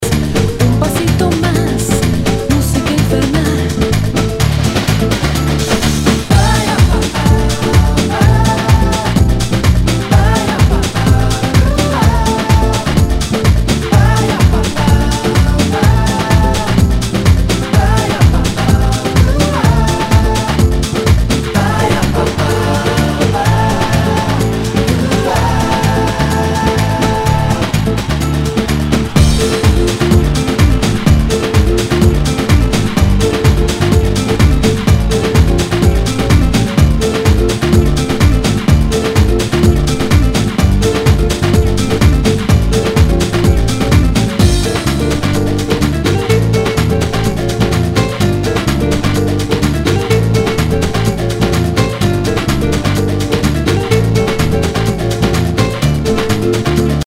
HOUSE/TECHNO/ELECTRO
ナイス！ラテン・ヴォーカル・ハウス！
プレイ可能ですが盤に歪みあり（相性が悪いと音飛びするかもしれません）